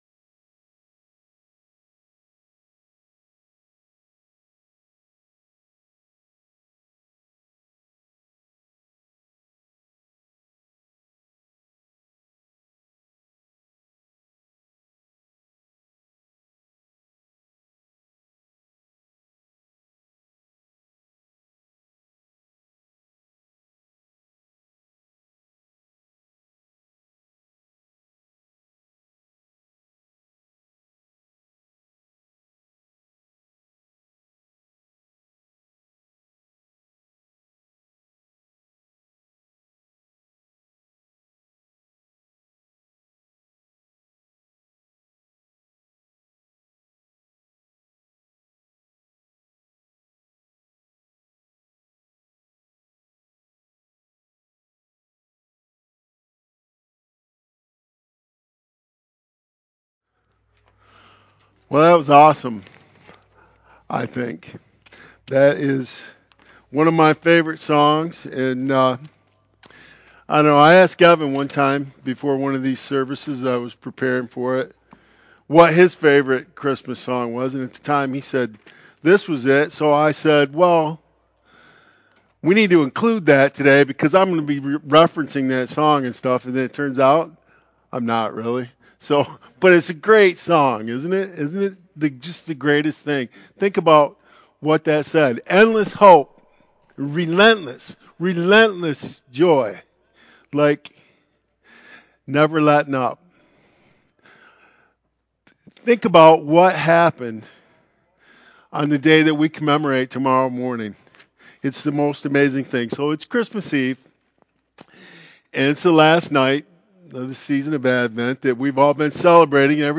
The-Greatest-Light-Sermon-Audio-CD.mp3